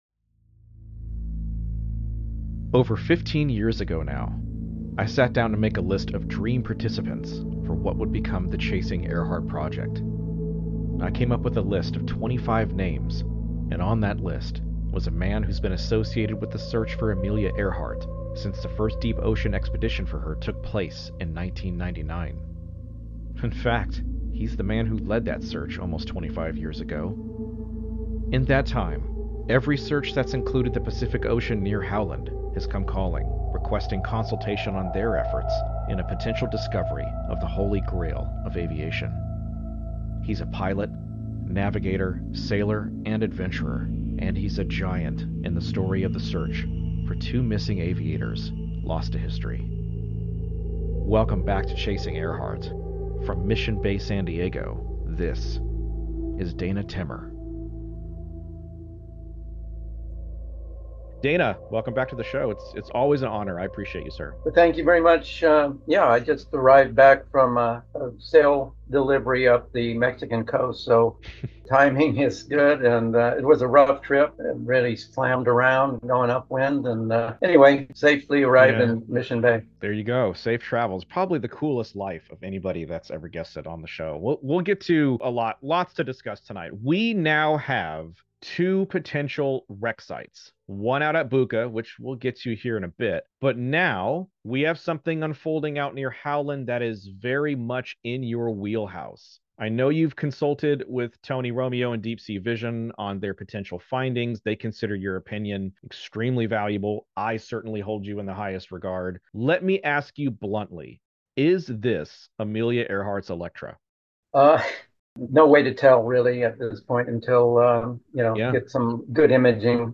Howland Island Landing: A Conversation